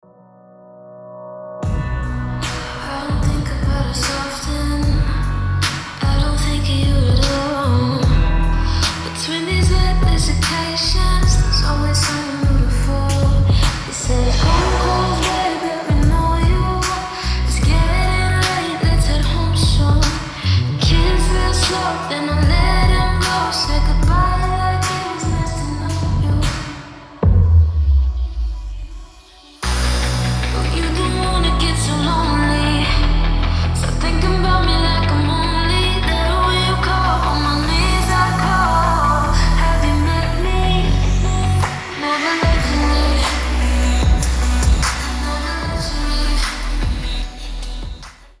объемная 8d музыка для наушников